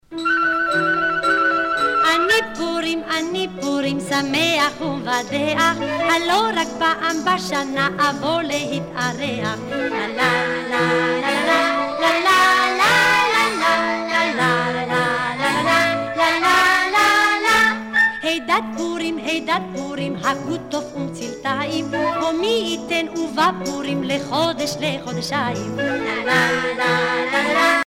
Cantilations bibliques
Pièce musicale éditée